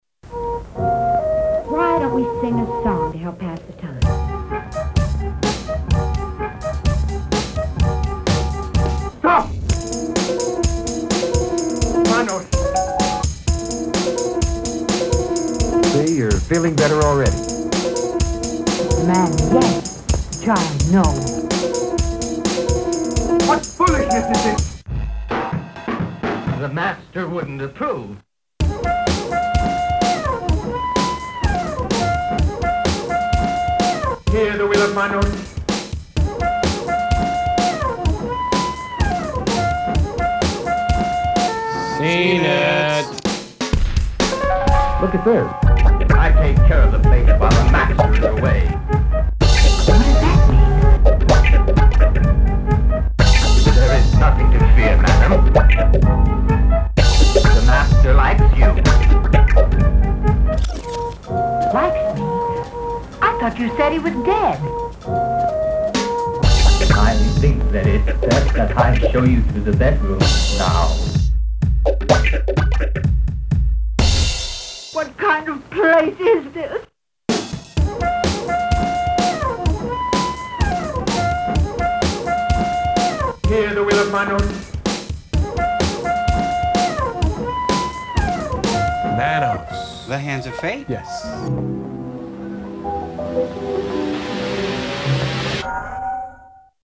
This track was created on a Korg Electribe sampling rhythm composer, in 2002.
Apart from the drum and percussion samples, the rest is constructed entirely from samples taken from the Mystery Science Theatre 3000 episode featuring the movie Manos: The Hands of Fate.